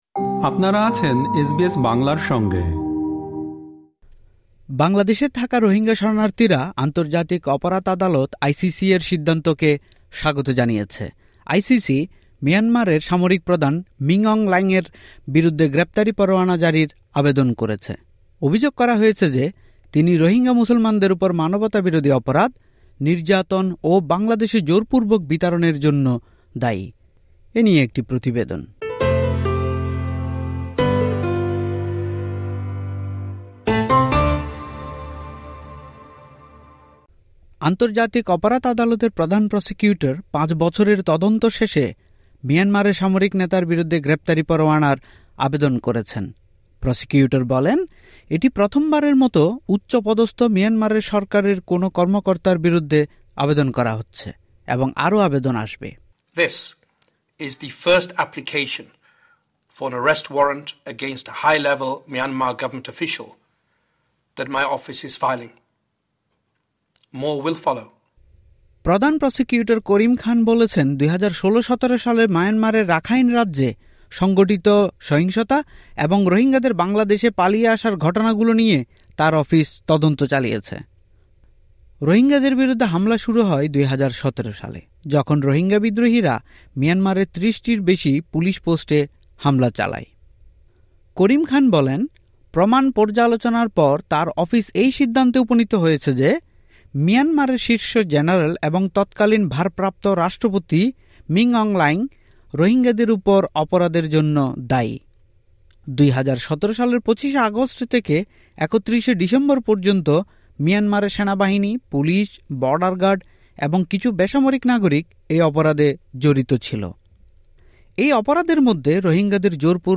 এ নিয়ে একটি প্রতিবেদন।